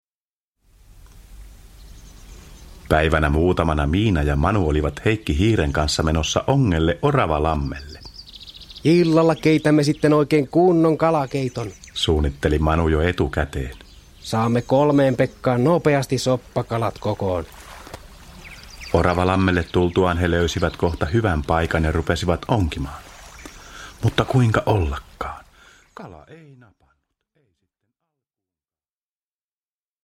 Miina ja Manu lohikäärmeen luolassa – Ljudbok – Laddas ner